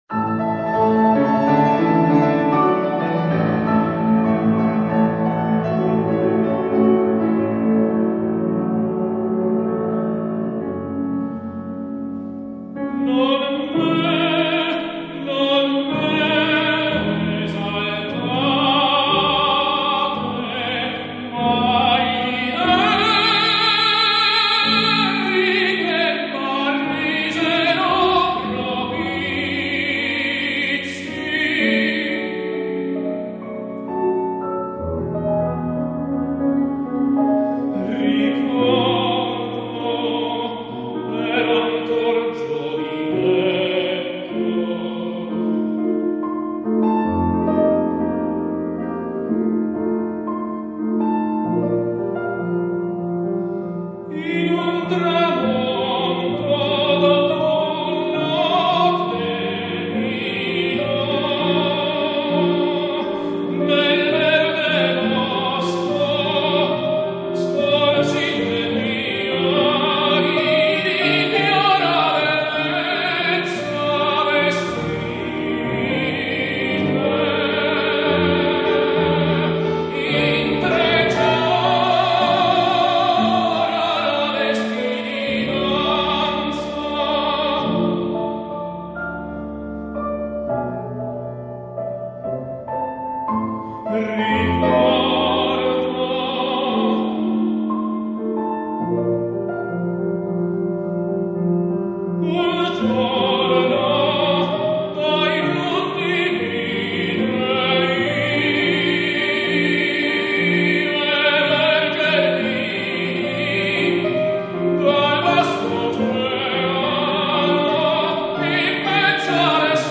[Tenor]
(mit Klavier)